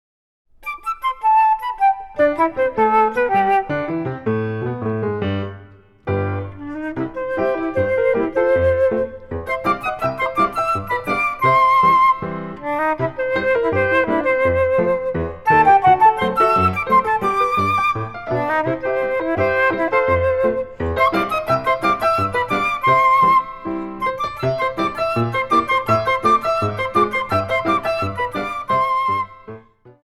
（フルート）